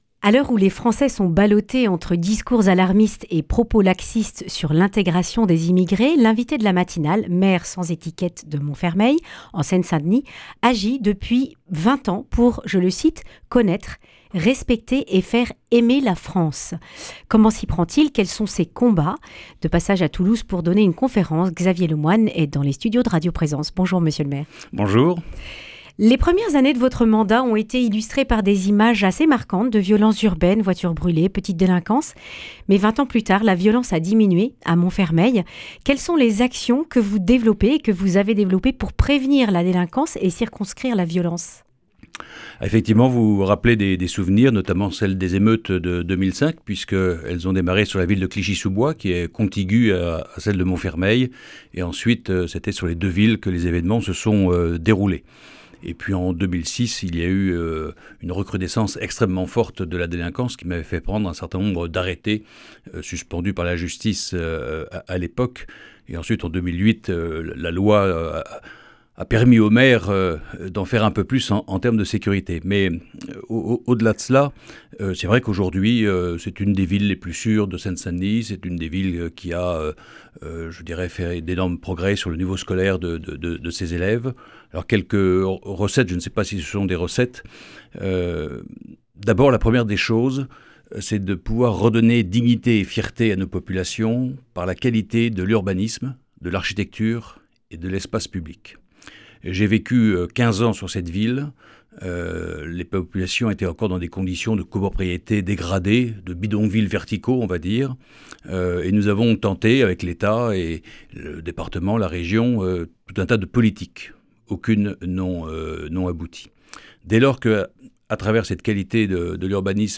A l’occasion d’un passage à Toulouse pour donner une conférence, Xavier Lemoine, maire de Montfermeil (Seine saint Denis) est passé par les studios de radio Présence. Il raconte comment il a réussi à endiguer la violence dans les quartiers sensibles en commençant par une ambitieuse politique de réhabilitation des logements et des espaces verts. L’accent a aussi été mis sur la culture pour permettre aux habitants issus de l’immigration de connaître et aimer la France.